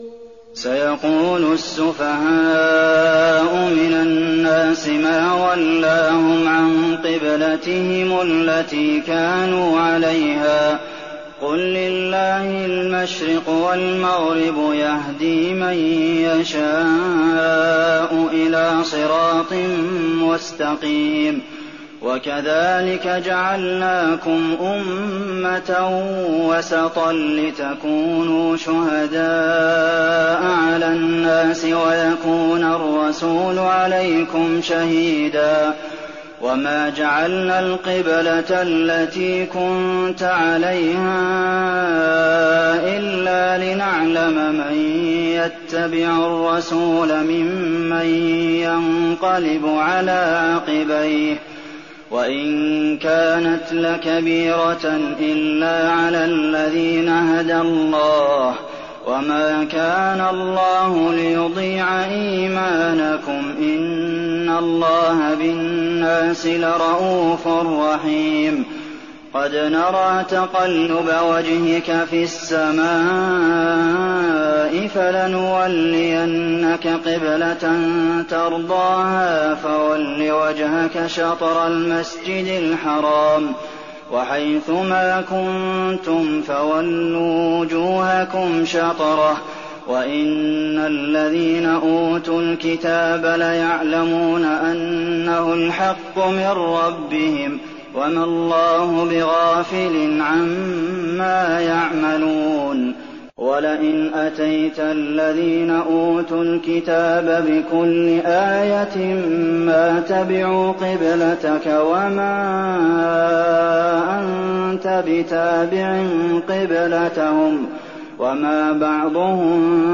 تراويح الليلة الثانية رمضان 1419هـ من سورة البقرة (142-202) Taraweeh 2nd night Ramadan 1419H from Surah Al-Baqara > تراويح الحرم النبوي عام 1419 🕌 > التراويح - تلاوات الحرمين